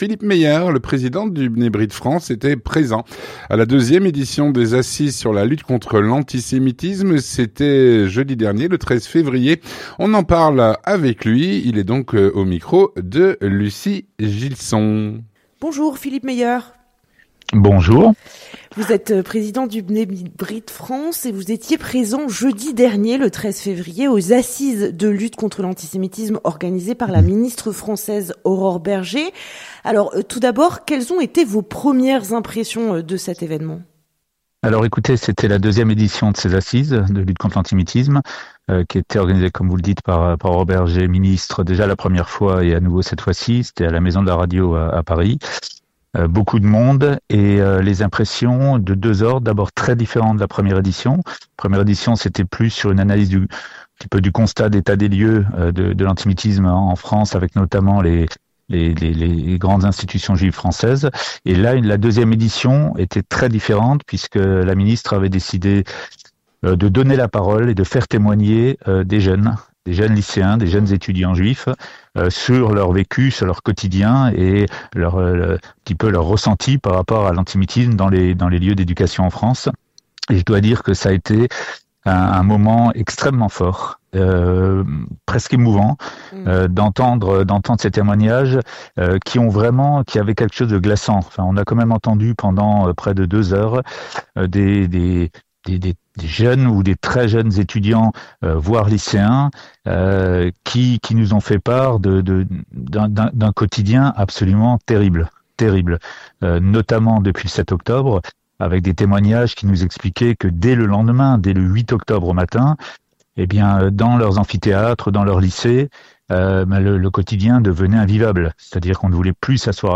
L'entretien du 18H - La 2ème édition des assises sur la lutte contre l’antisémitisme ont eu lieu le 13 Février dernier.